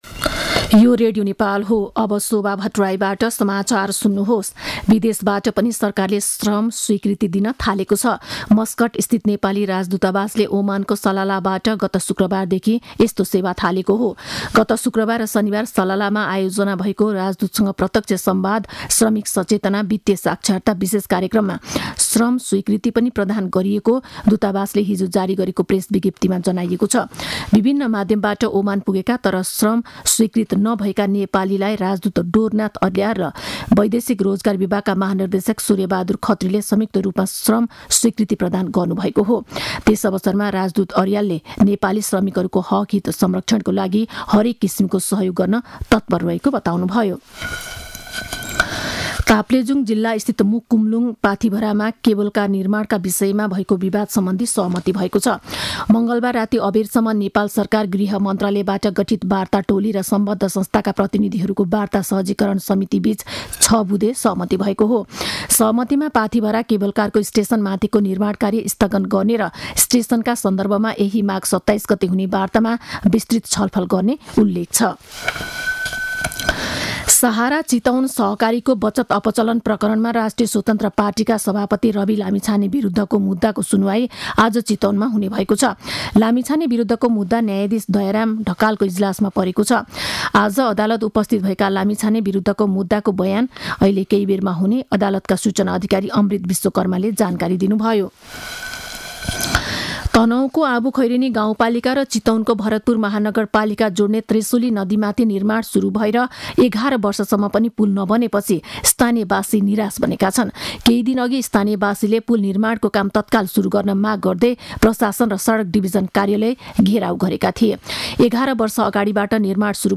मध्यान्ह १२ बजेको नेपाली समाचार : २४ माघ , २०८१